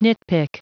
Prononciation du mot nitpick en anglais (fichier audio)
Prononciation du mot : nitpick